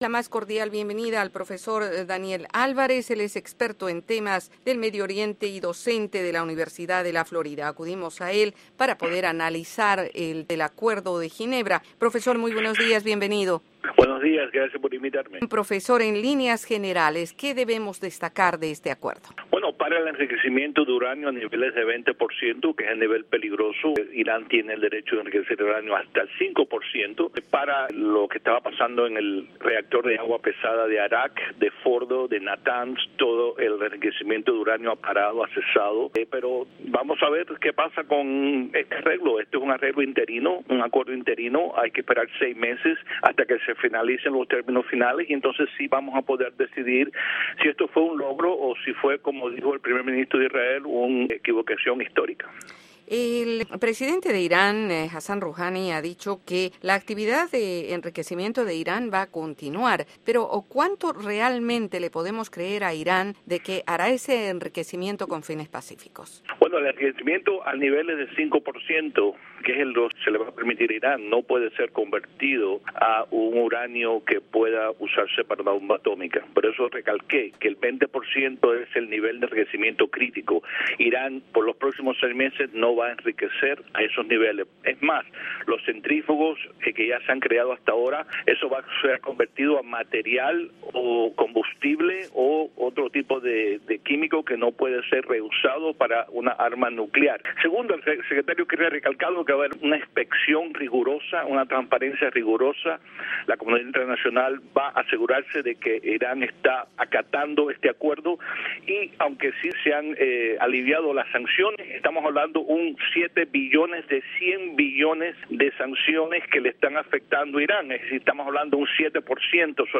El presidente Barack Obama intenta apaciguar a Israel, mientras en casa los legisladores advierten sobre más y más fuertes sanciones en caso de que se caiga el acuerdo. La Voz de América habló con un experto.